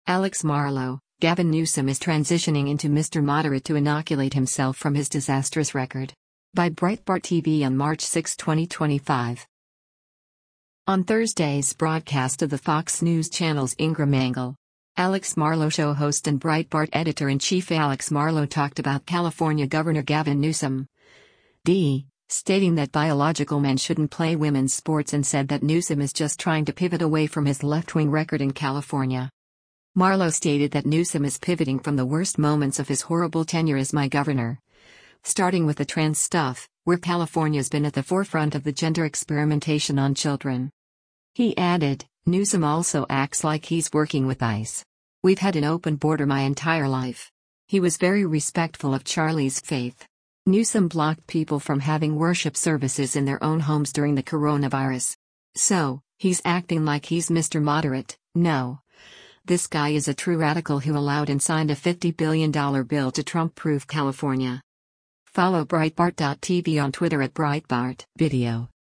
On Thursday’s broadcast of the Fox News Channel’s “Ingraham Angle,” “Alex Marlow Show” host and Breitbart Editor-in-Chief Alex Marlow talked about California Gov. Gavin Newsom (D) stating that biological men shouldn’t play women’s sports and said that Newsom is just trying to pivot away from his left-wing record in California.